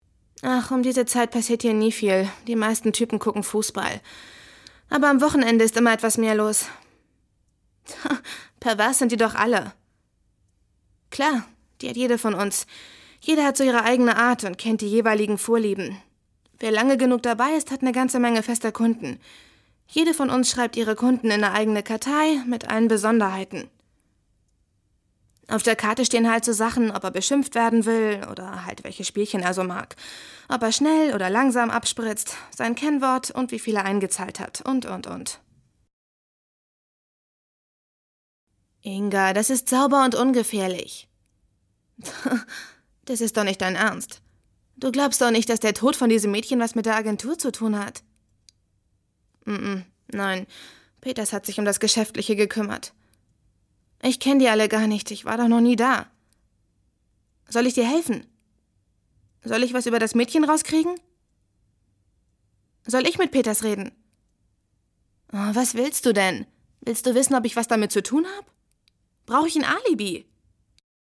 hell, fein, zart
Jung (18-30), Mittel minus (25-45)
Lip-Sync (Synchron)